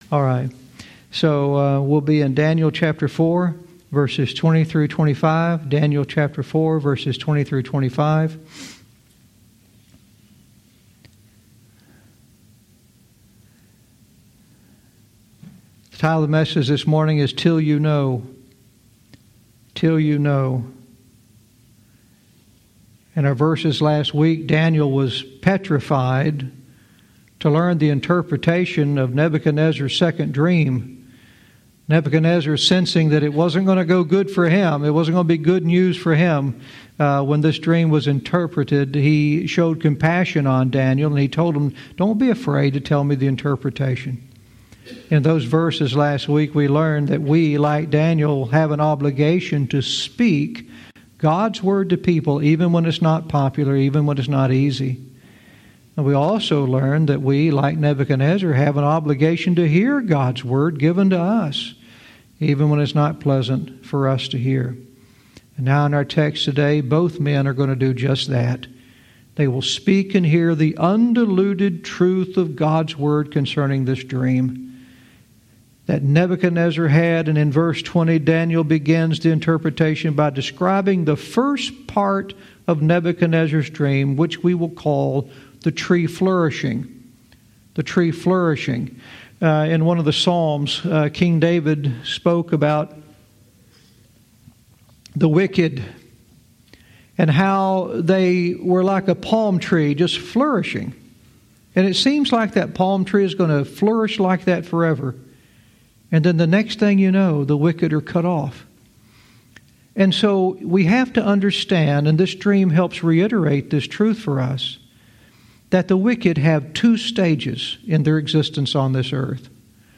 Verse by verse teaching - Daniel 4:20-25 "Till You Know"